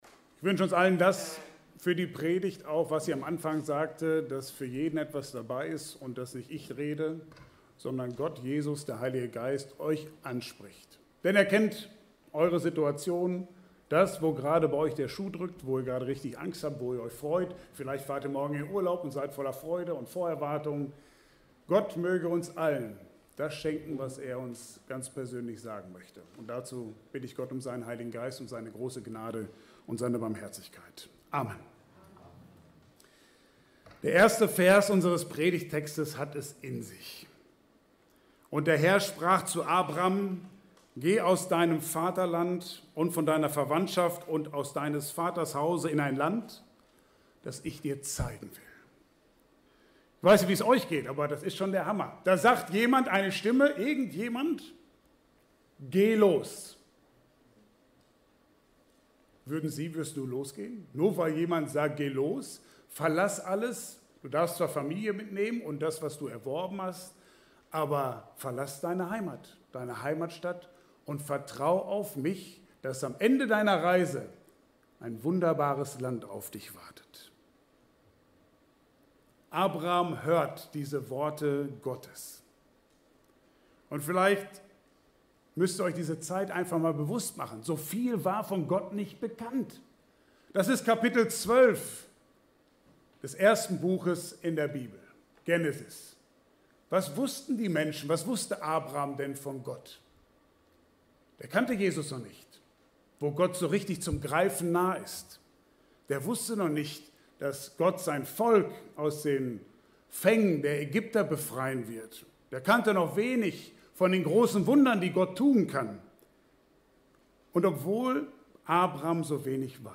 Passage: 1. Mose 12,1–4a Dienstart: Gottesdienst « Eigentor, Bedenkzeit und Neuanfang Für die bin ich wie Luft, als ob ich nicht existieren würde, wie gestorben.